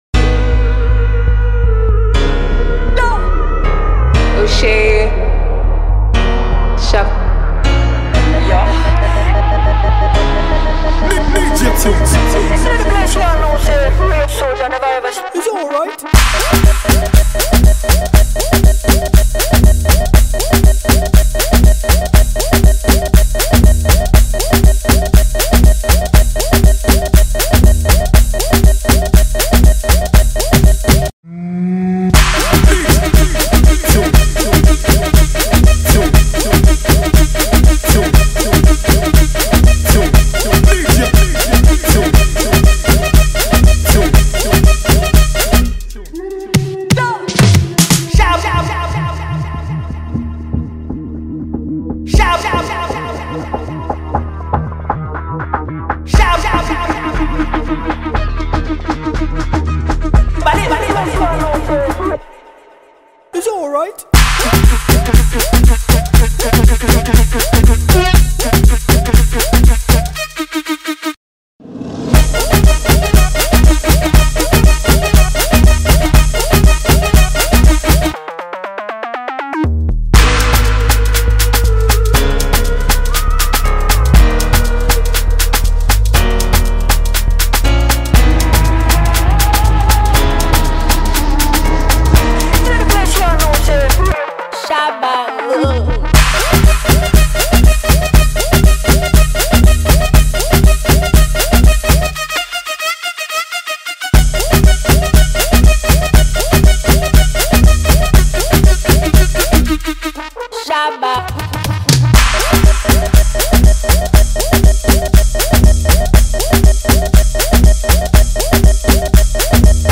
fresh party hit